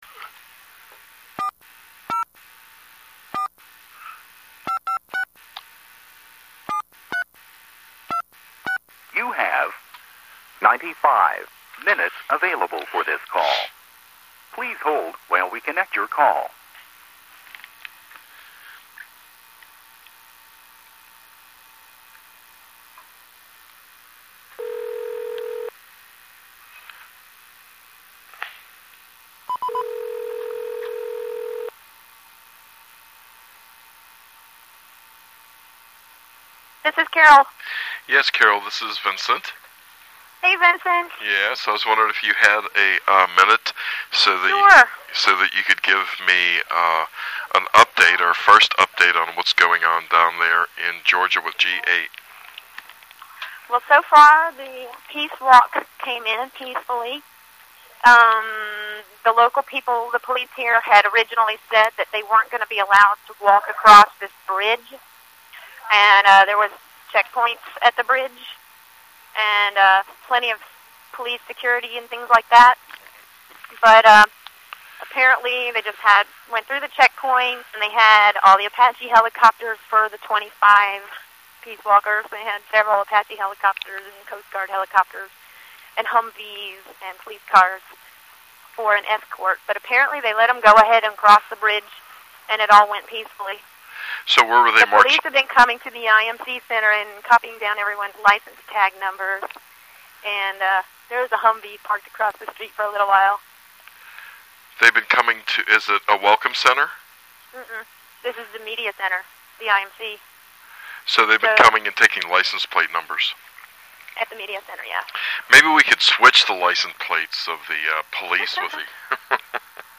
first of a series of phone interviews with the "NO G8 IMC"